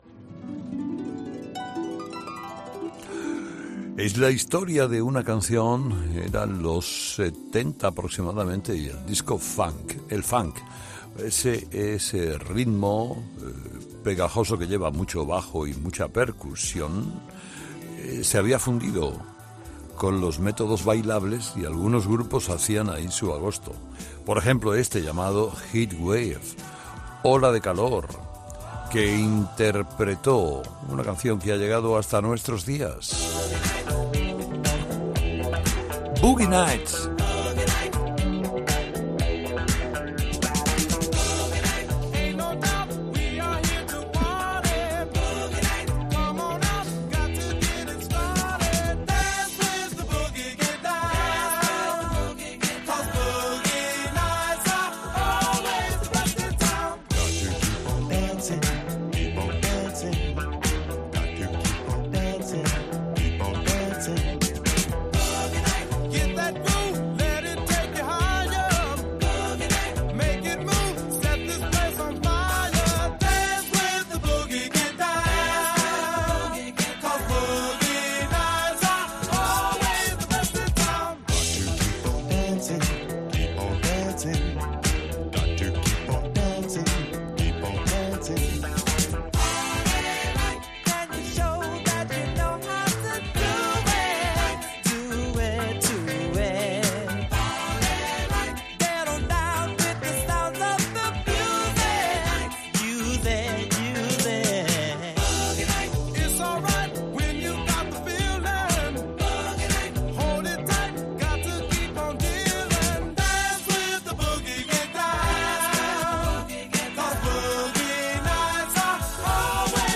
El funk-disco